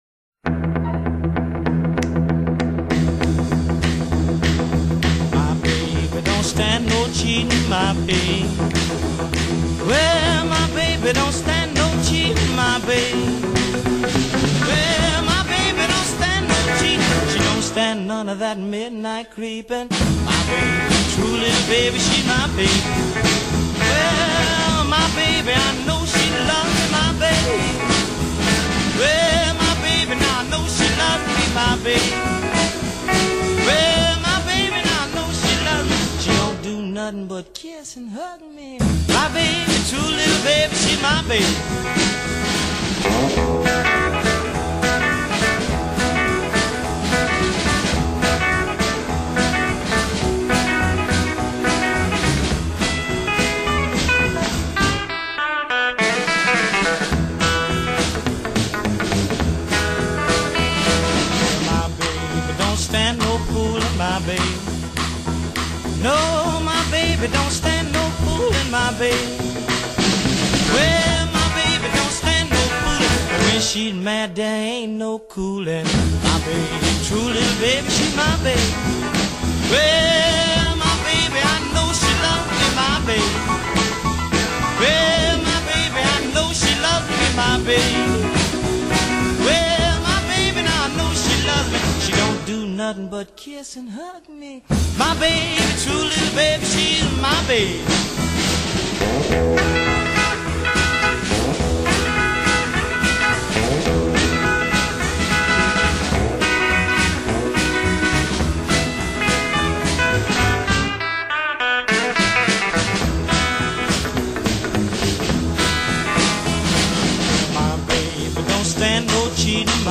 smokin' Rock 'N Roll!!!